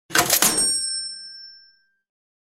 Caching-sound-effect.mp3